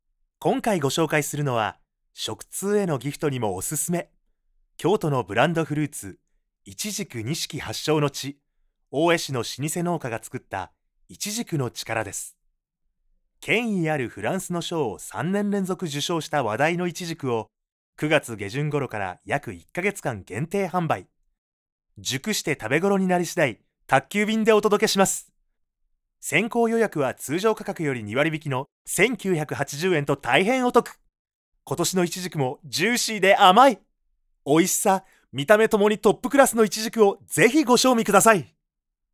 I can adapt my voice to your needs, from a calm tone to a bouncy, energetic voice.
Can speak Kansai dialect.
Serious
mail-order program-style (e.g. TV)